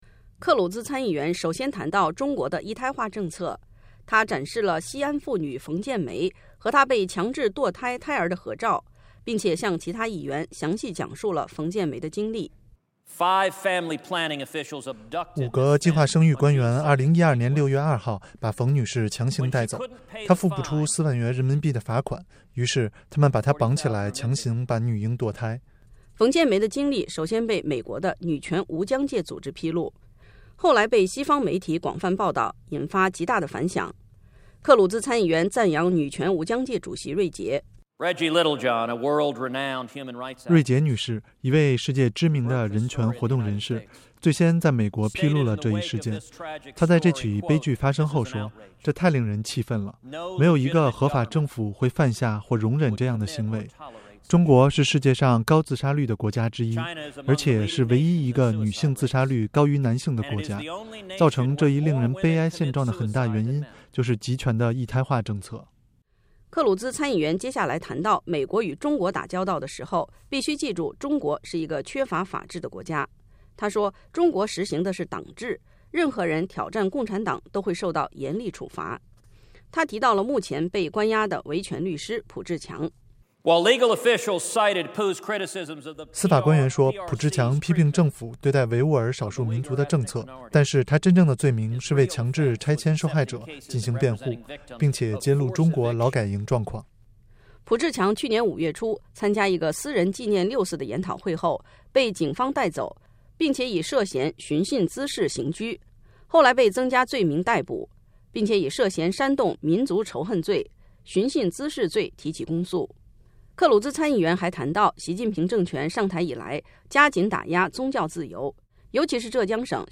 美国国会共和党参议员、总统大选参选人克鲁兹（Senator Ted Cruz）11月4号星期三在全体院会专题发言批评中国人权状况。